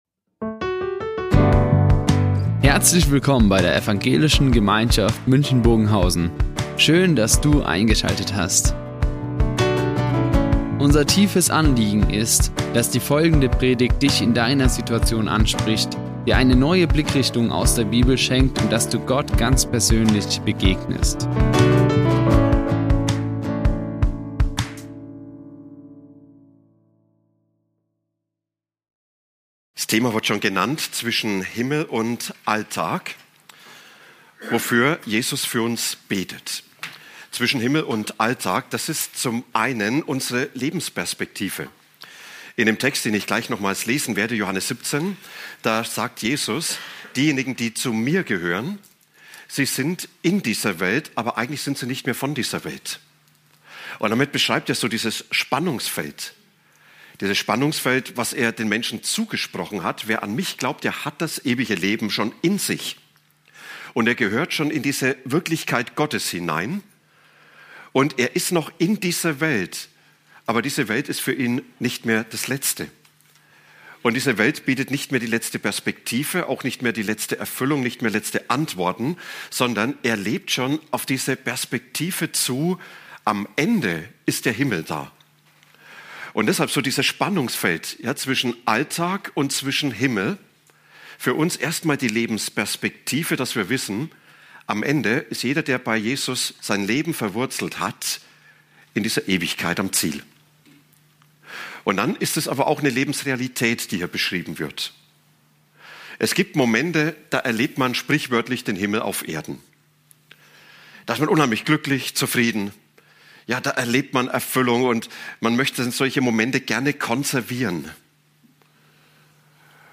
Die Aufzeichnung erfolgte im Rahmen eines Livestreams.